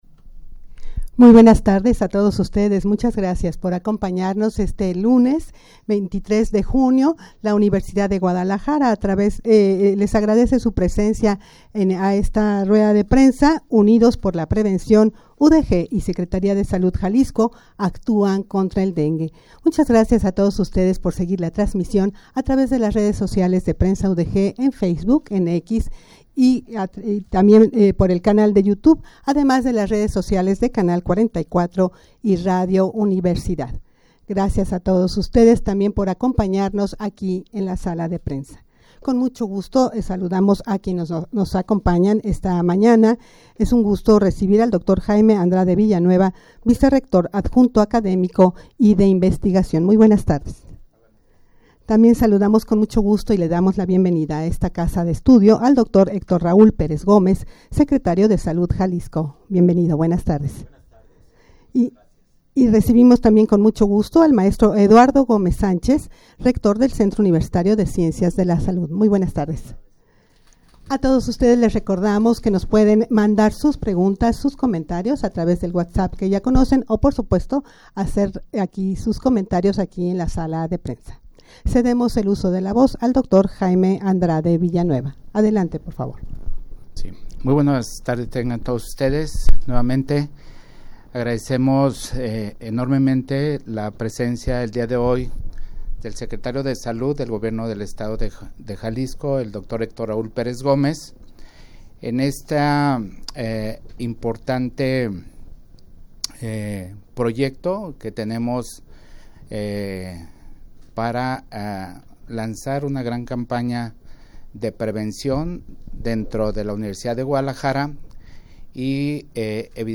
Audio de la Rueda Prensa
rueda-de-prensa-unidos-por-la-prevencion-udeg-y-secretaria-de-salud-jalisco-actuan-contra-el-dengue.mp3